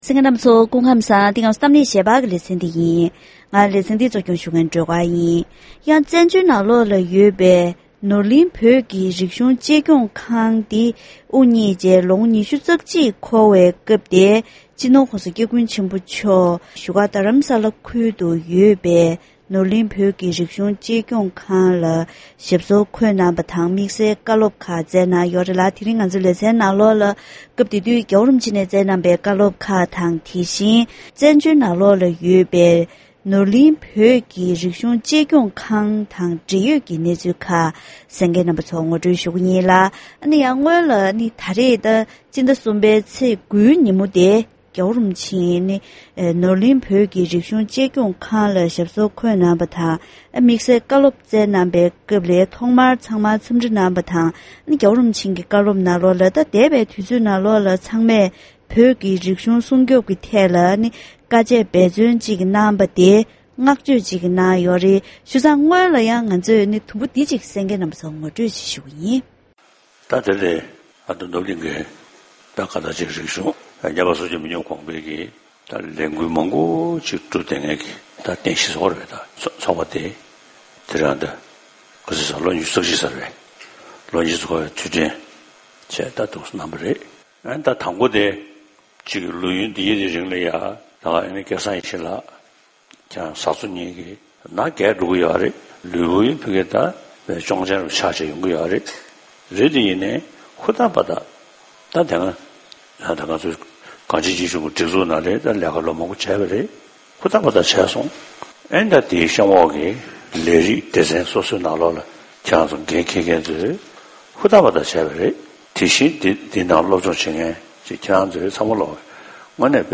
༄༅། །དེ་རིང་གི་གཏམ་གླེང་ཞལ་པར་གྱི་ལེ་ཚན་ནང་བཙན་བྱོལ་ནང་ཡོད་པའི་ནོར་གླིང་བོད་ཀྱི་རིག་གཞུང་གཅེས་སྐྱོང་ཁང་འདི་དབུ་བརྙེས་ནས་ལོ་ངོ་༢༡འཁོར་བའི་མཛད་སྒོའི་ཐོག་སྤྱི་ནོར་༧གོང་ས་༧སྐྱབས་མགོན་ཆེན་པོ་མཆོག་ནས་མི་སྒེར་གྱི་མི་ཚེ་རྫོགས་འགྲོ་ཡི་ཡོད་ན་ཡང་མི་རིགས་ཤིག་གི་རིག་གཞུང་འདི་མུ་མཐུད་གནས་དགོས་རྒྱུ་གལ་ཆེ་ཡིན་པའི་བཀའ་སློབ་གནང་ཡོད་པ་དང་། བཙན་བྱོལ་ནང་གི་ནོར་གླིང་བོད་ཀྱི་རིག་གཞུང་གཅེས་སྐྱོང་ཁང་ངོ་སྤྲོད་ཞུས་པ་ཞིག་གསན་རོགས་གནང་།